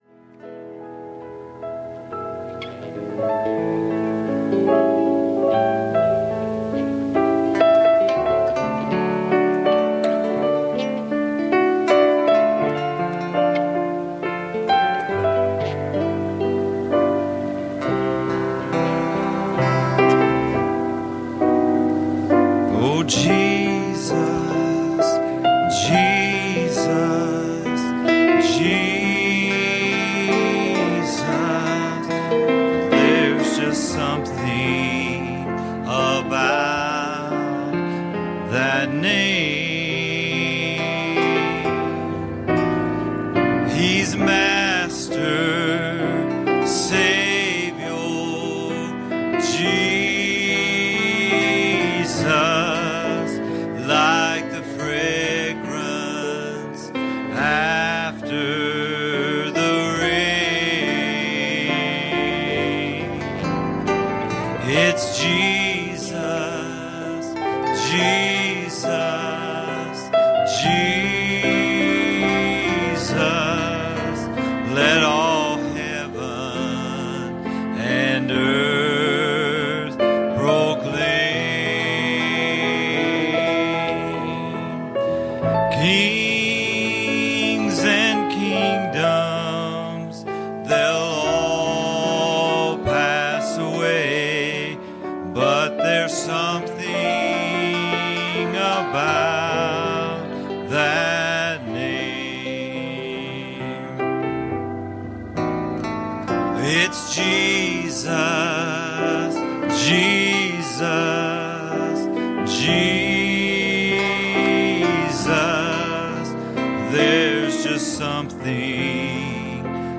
Communion Service
Service Type: Sunday Evening